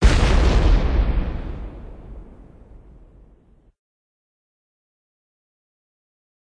explosion_medium.ogg